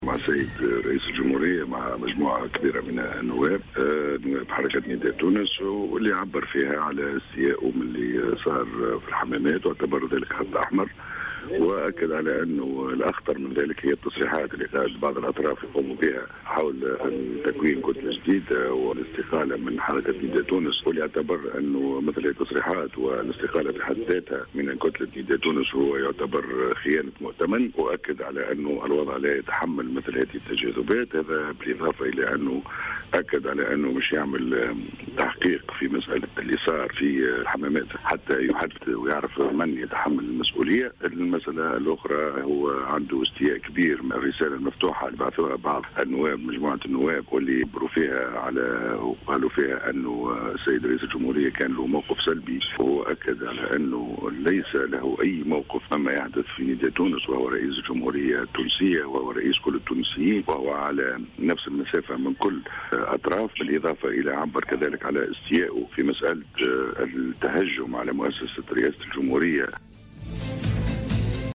أكد النائب عن حزب نداء تونس عبد العزيز القطي في تصريح ل"الجوهرة أف أم" بعد لقاء رئيس الجمهورية الباجي قائد السبسي بقصر قرطاج أن رئيس الجمهورية قرّر فتح تحقيق على خلفية الأحداث التي جدّت أمس بالحمامات خلال اجتماع المكتب التنفيذي للحزب.